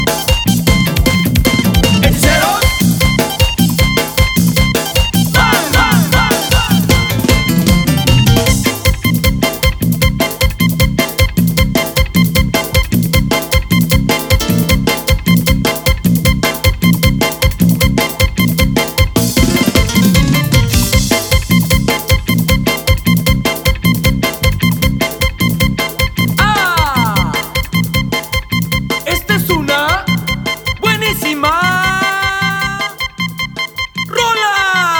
Música Mexicana Latin